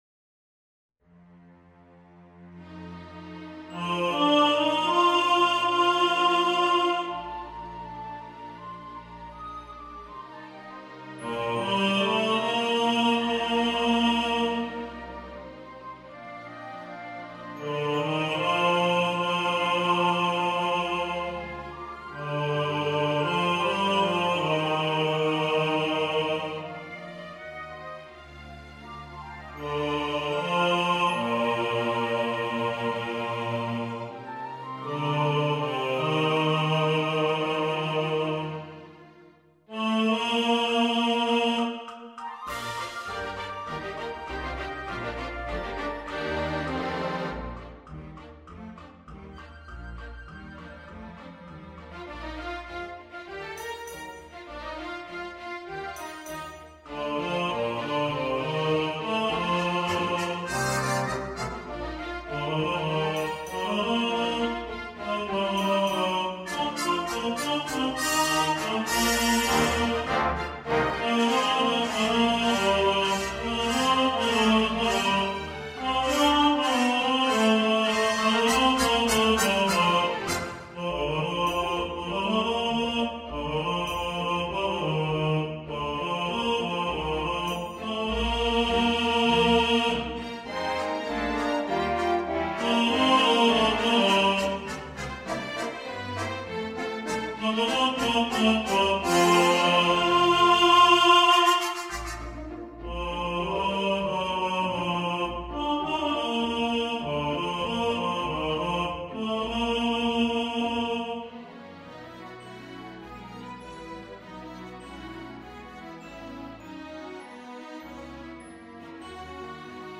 Santa Claus Is Coming To Town Tenor | Ipswich Hospital Community Choir